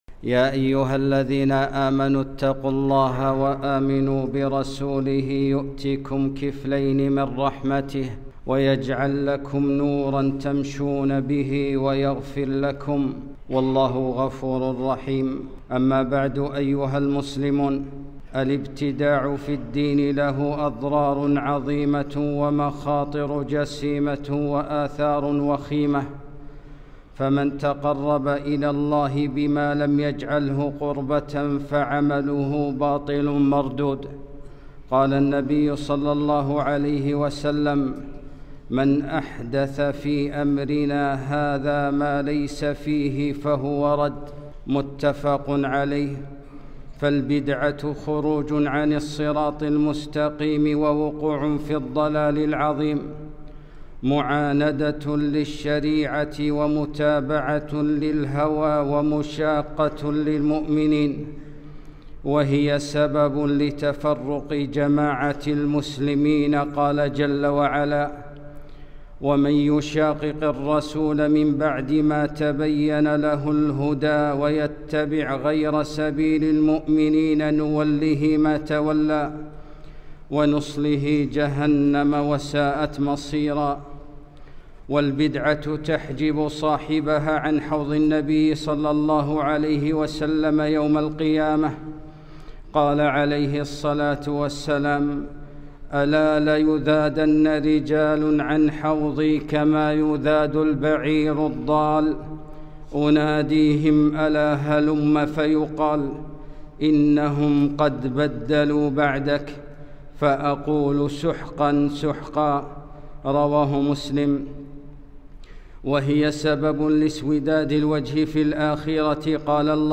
خطبة - لا تقدموا بين يديّ الله ورسوله